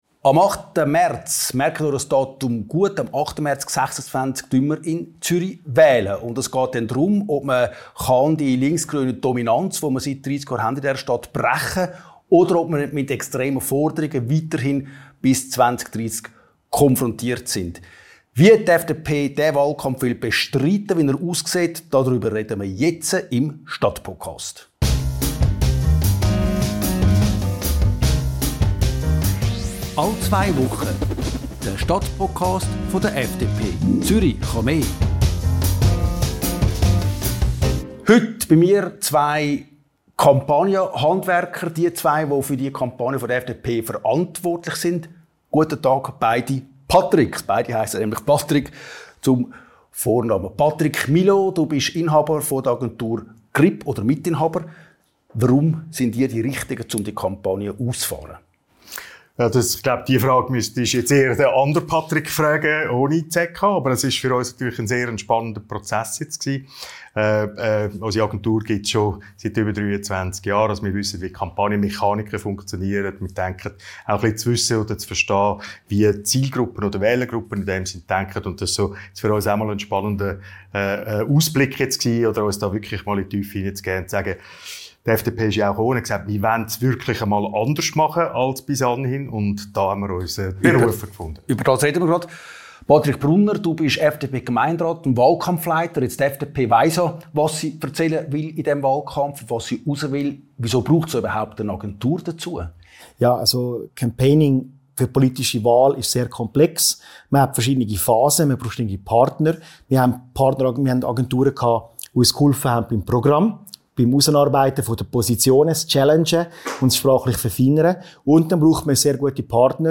Ein Gespräch über Politwerbung und die Grenzen der Meinungsgestaltung.